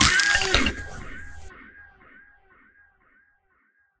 PixelPerfectionCE/assets/minecraft/sounds/mob/horse/skeleton/hit2.ogg at c12b93b9c6835a529eb8ad52c47c94bf740433b9